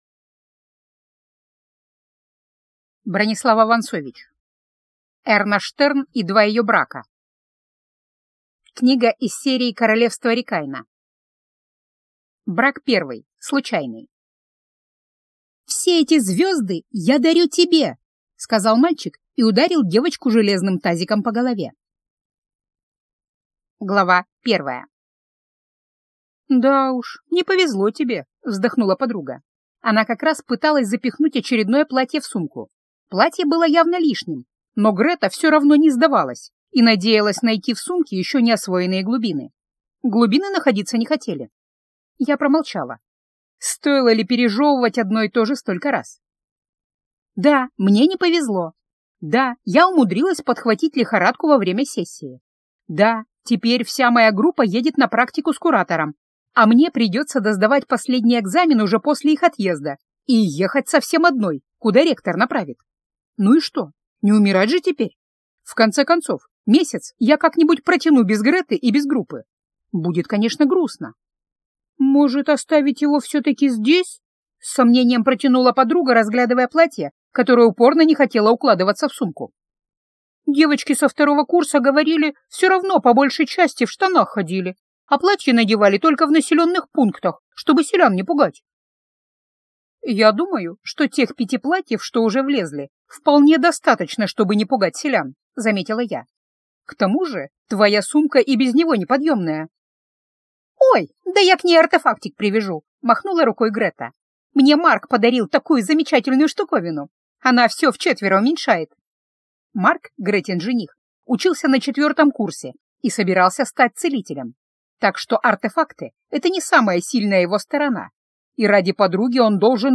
Аудиокнига Эрна Штерн и два ее брака - купить, скачать и слушать онлайн | КнигоПоиск